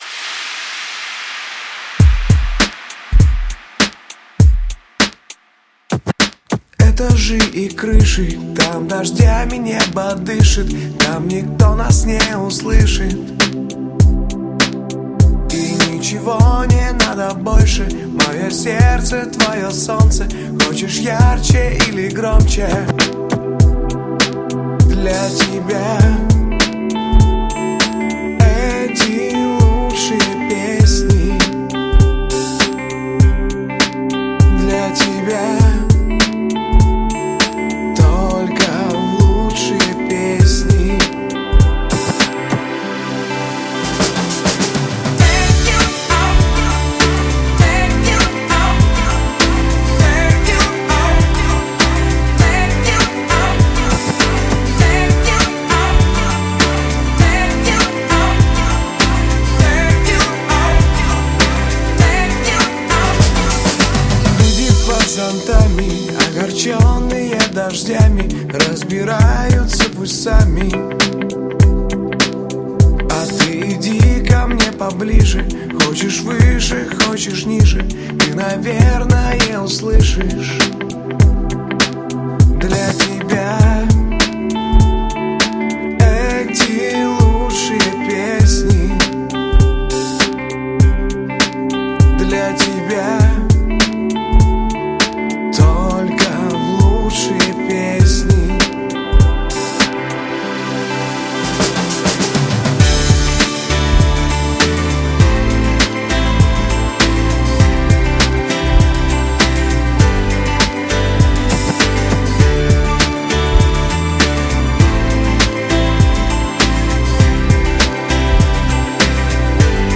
Красивый,танцевальный,трек.
Подстиль: House Music